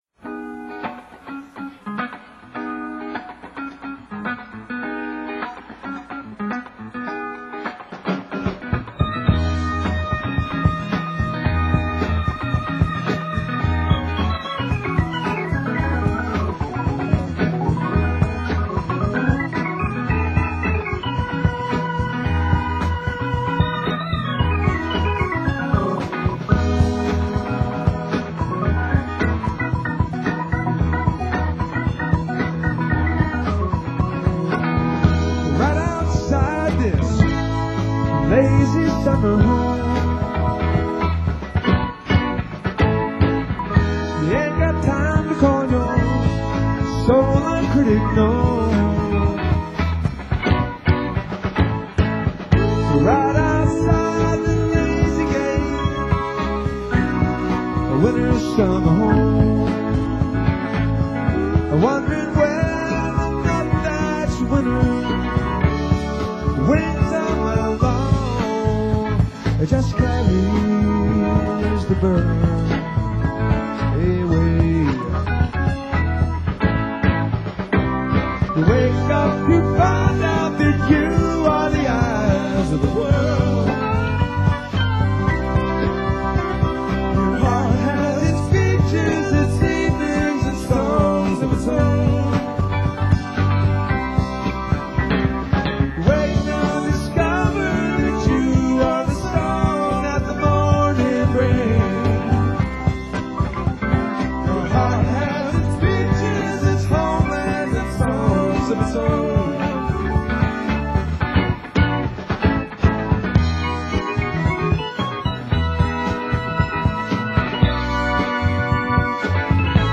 keyboards & vocals
drums
bass & vocals
guitar & vocals